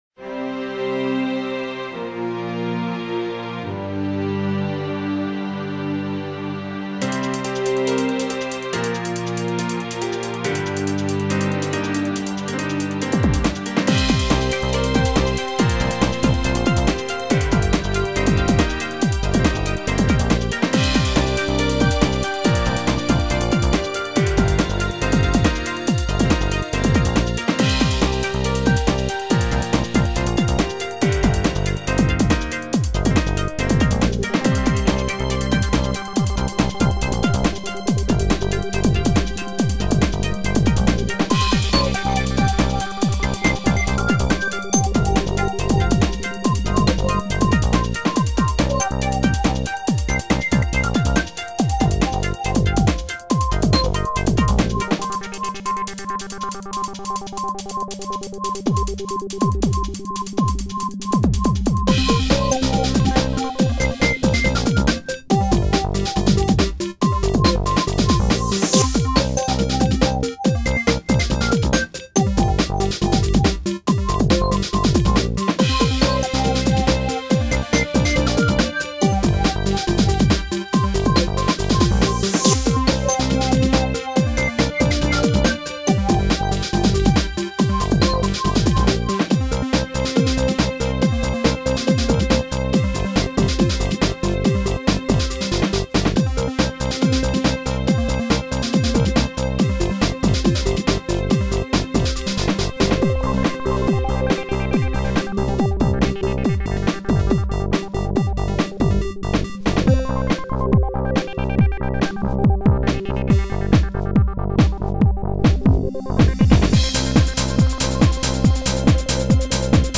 Uplifting music with break-beat|chemical drums. Sounds a lot like news jingle music, has a lot of changes in composition.
news_jingle_0.mp3